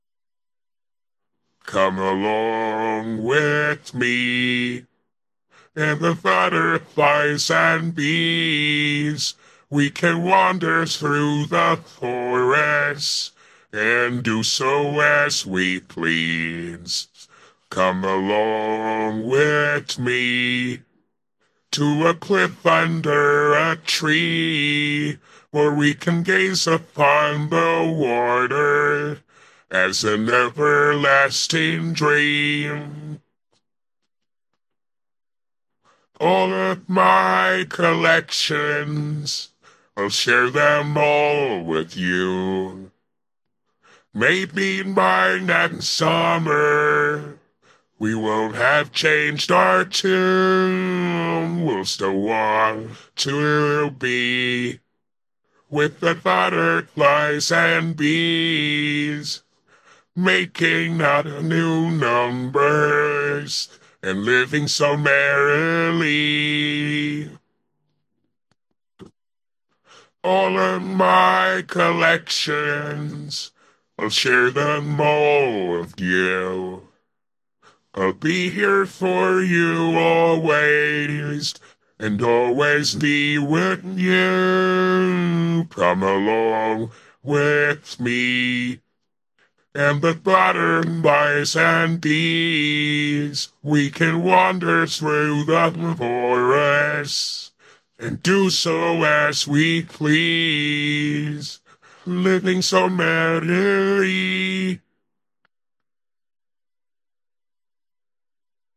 Captain Gantu (Lilo & Stich) - (RVC V2 | RVMPE | Legacy Core Pretrain) - (115 Epochs) AI Voice Model